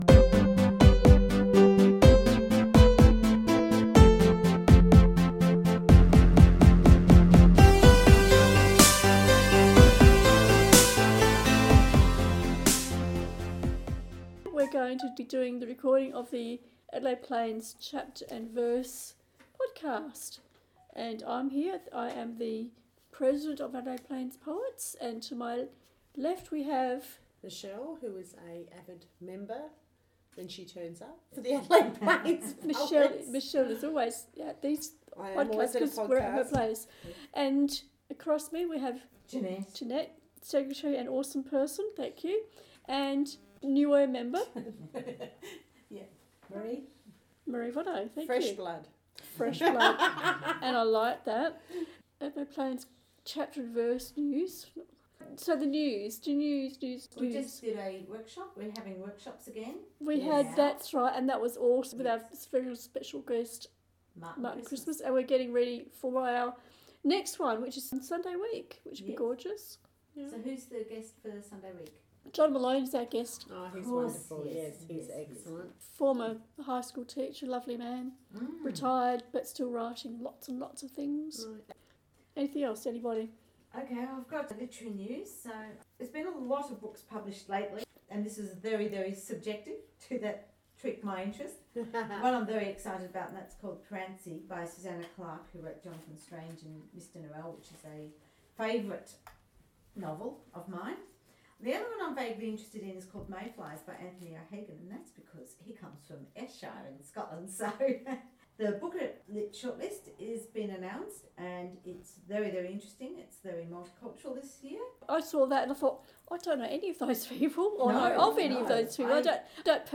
Adelaide Plains Chapter and Verse monthly podcast recorded Wednesday 12th September, Gawler South.